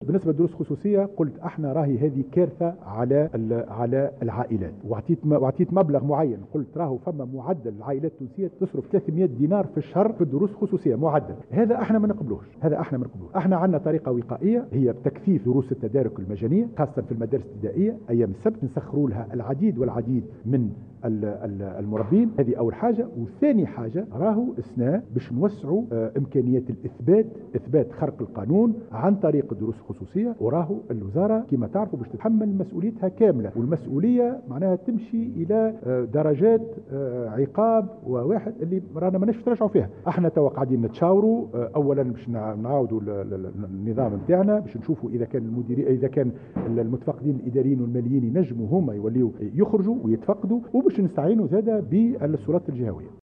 وأضاف خلال ندوة صحفية عقدها اليوم الخميس بمقر الوزارة بمناسبة العودة المدرسية، أن الدروس الخصوصية أثقلت كاهل العائلات التونسية التي تنفق ما معدله 300 دينار شهريا على هذه الدروس.